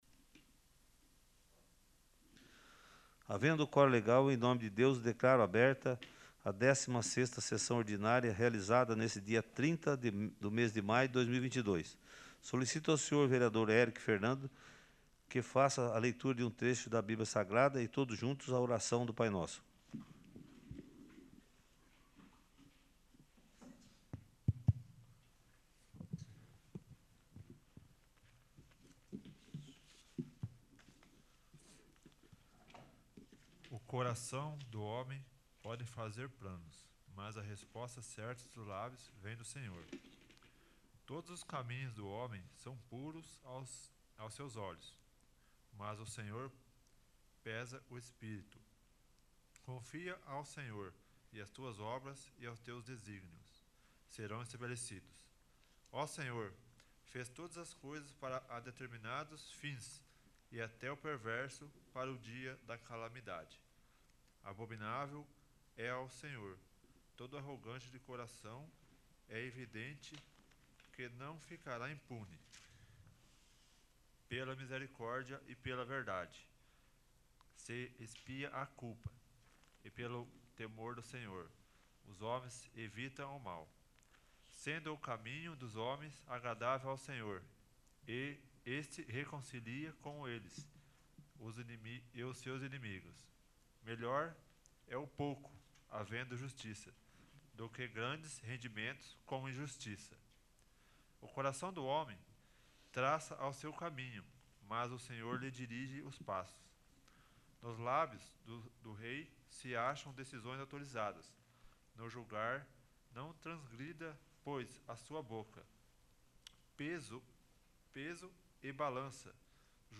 15º. Sessão Ordinária